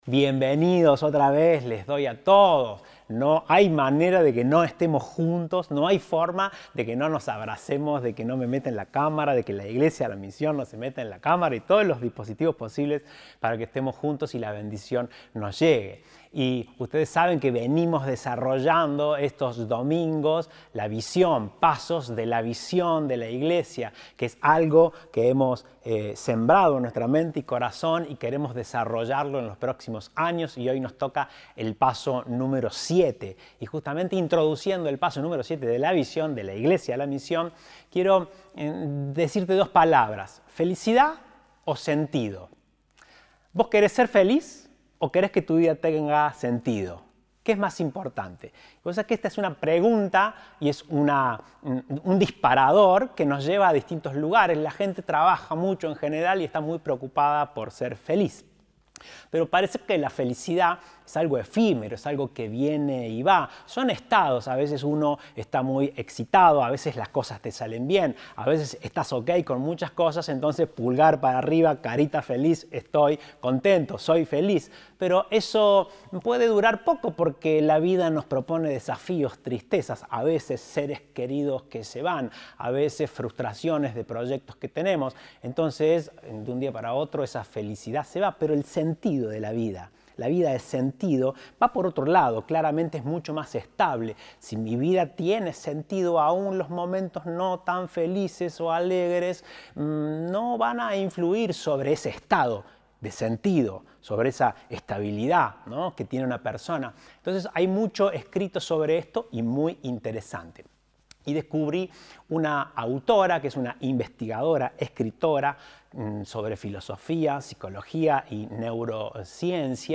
Compartimos el mensaje del Domingo 23 de Mayo de 2021 El siguiente es el video de la transmisión de la reunión virtual.
Compartimos el mensaje del Domingo 23 de Mayo de 2021